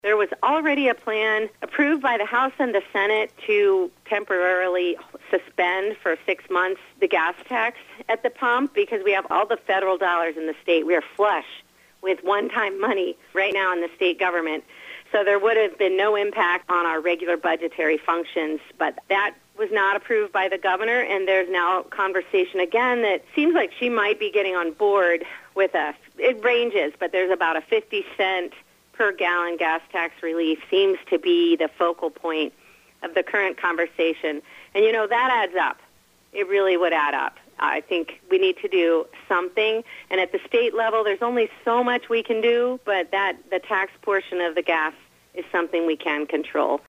Adrian, MI – Gas prices in Lenawee County are nearing, or are now over, $5 per gallon for regular unleaded…and our local representative in Lansing was asked about it during a recent 7:40am break.